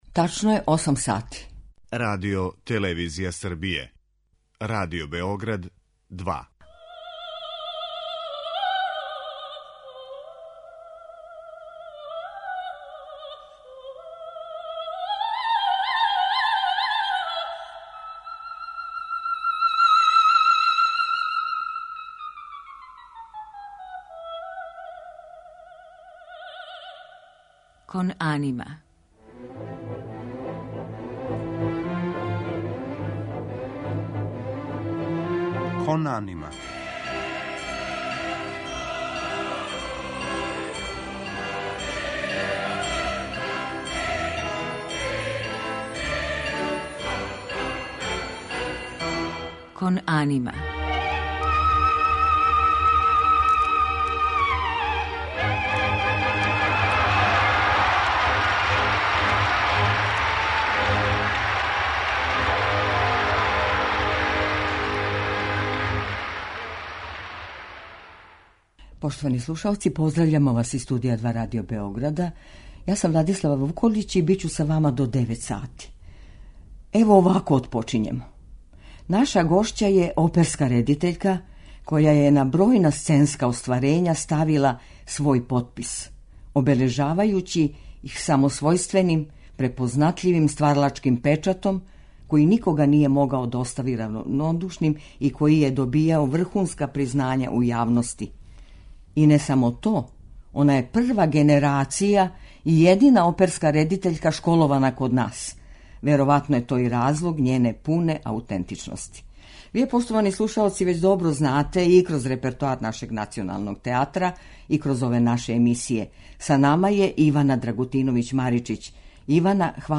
У музичком делу биће емитовани дуети и арије из најпознатијих опера у извођењу младих полазника Оперског студија Народног позоришта.